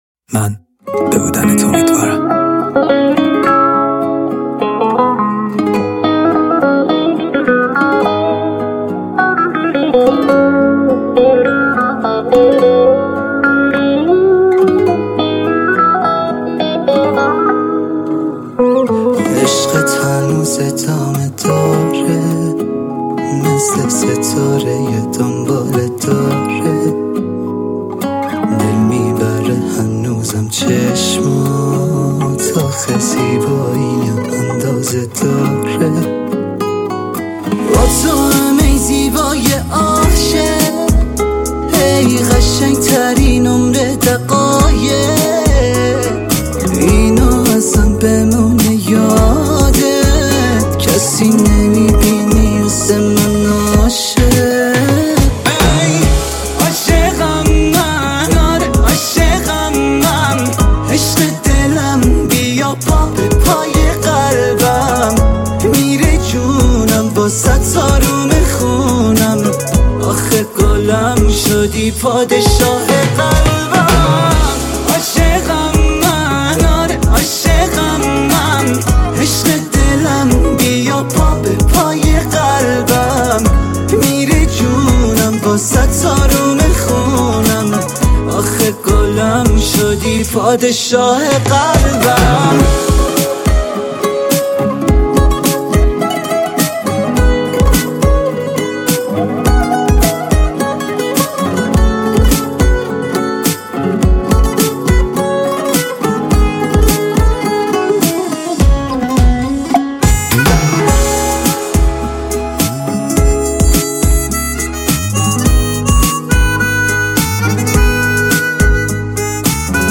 عالیه صدات پسررررررررر خیلی آروم با احساس باملودی آروم😜